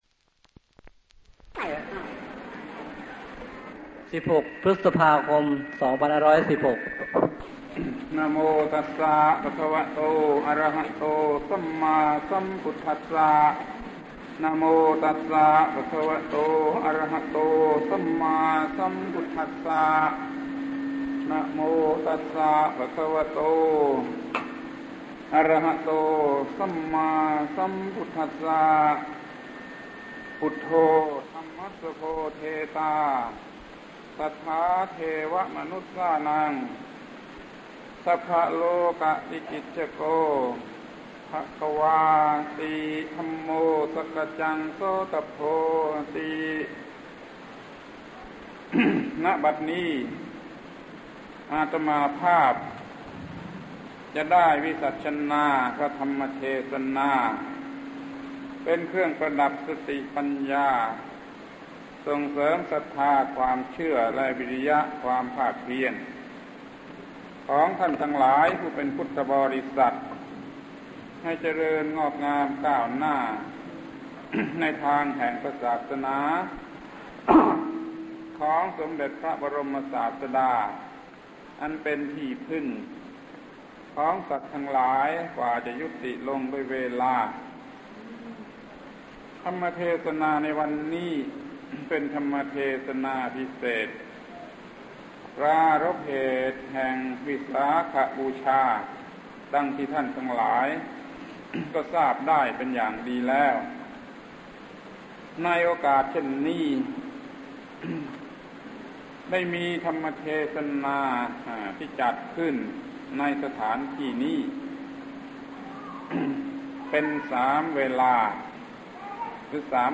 พระธรรมโกศาจารย์ (พุทธทาสภิกขุ) - วิสาขบูชาเทศนา ปี 2516 กัณฑ์ 1